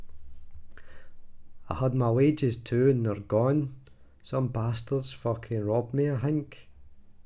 glaswegian_audio
glaswegian
scottish